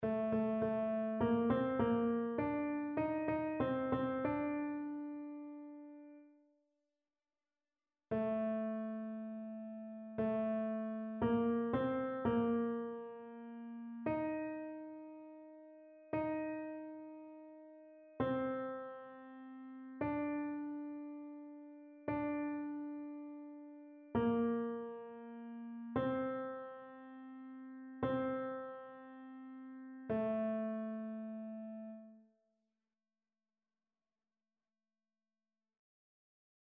TénorBasse